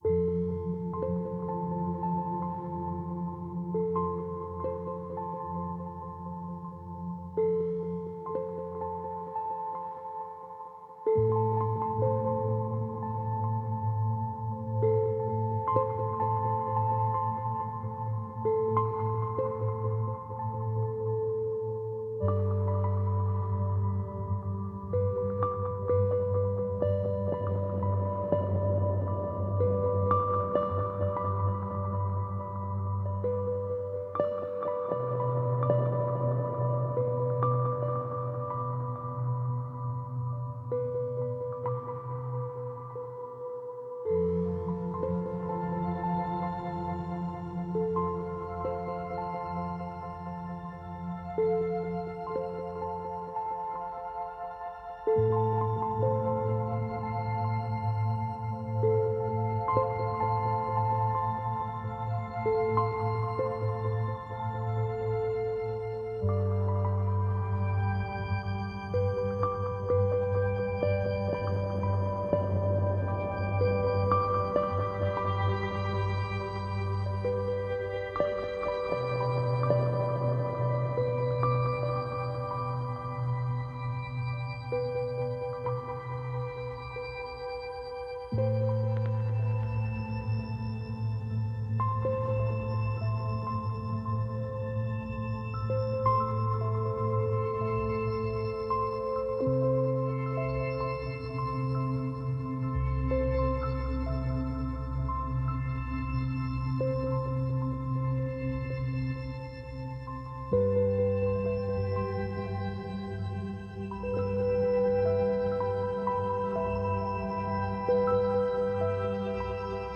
Aquatic pings and strange synths float in spacious reverb.